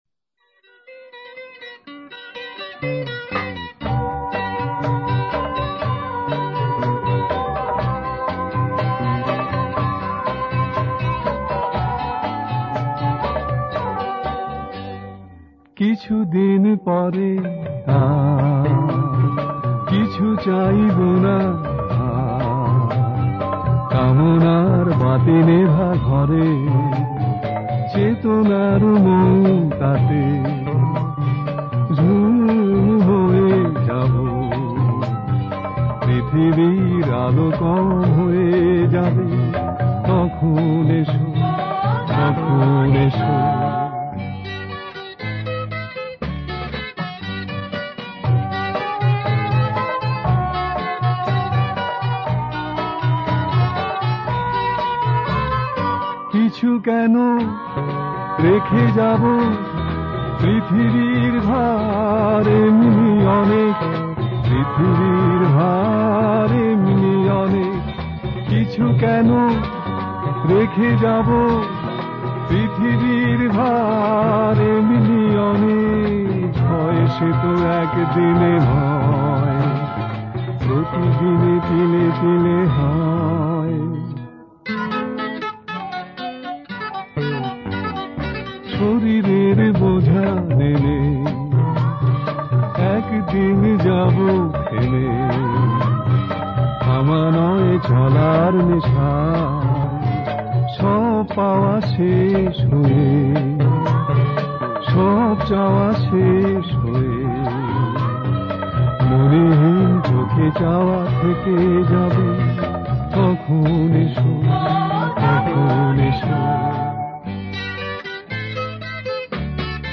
The only problem was that his range was rather limited.